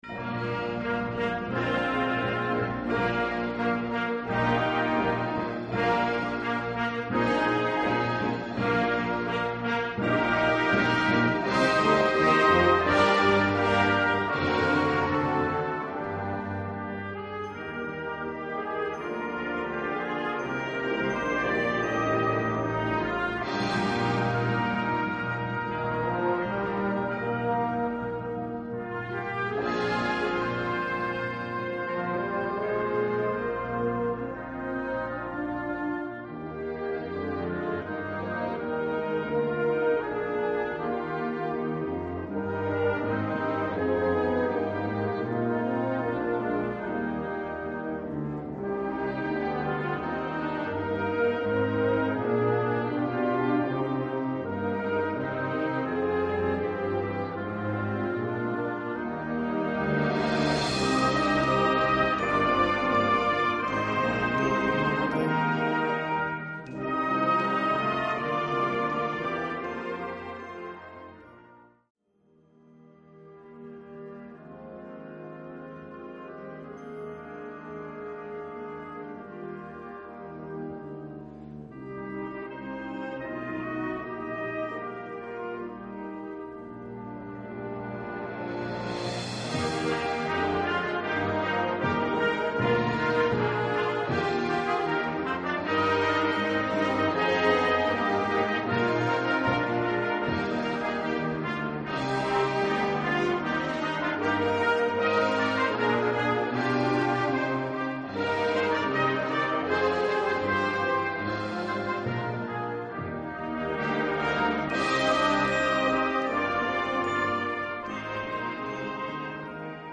Gattung: Weihnachtslied
Besetzung: Blasorchester
für Blasorchester